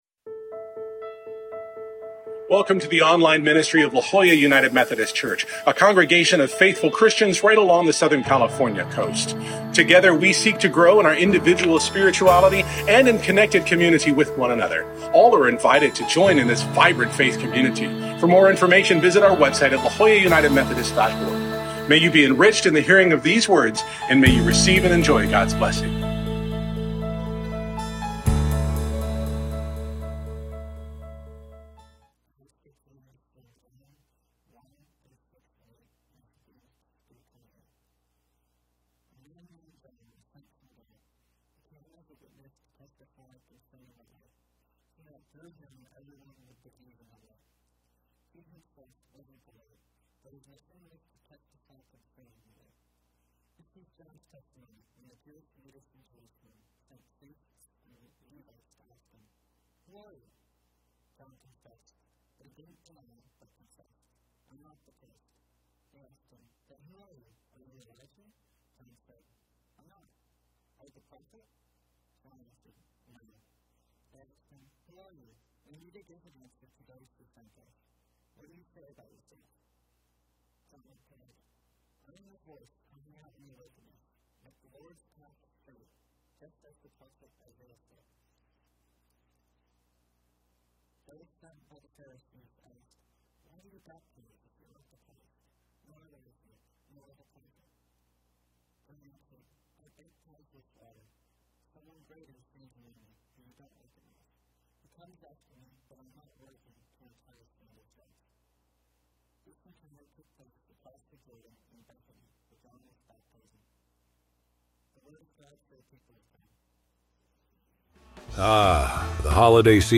Scripture: John 1:6-8, 19-28 worship bulletin Sermon Note Full Worship Video Share this: Print (Opens in new window) Print Share on X (Opens in new window) X Share on Facebook (Opens in new window) Facebook